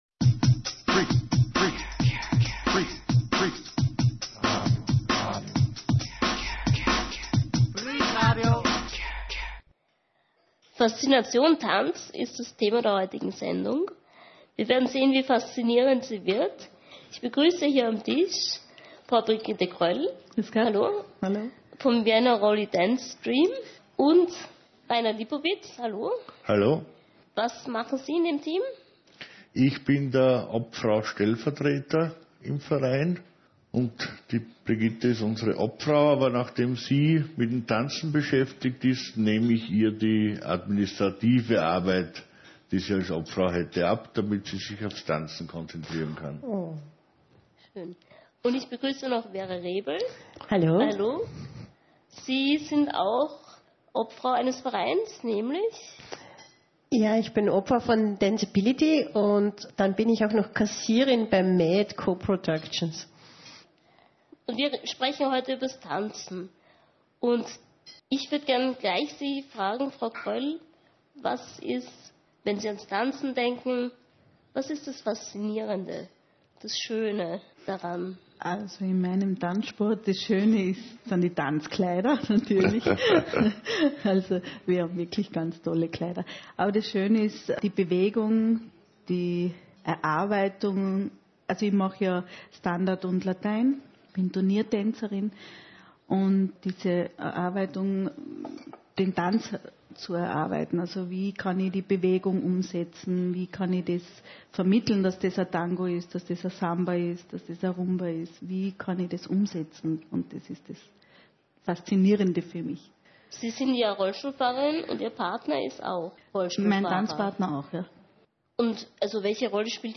In dieser Sendung erzählen zwei leidenschaftliche Tänzerinnen von den schönen Seiten und Herausforderungen des Tanzsports.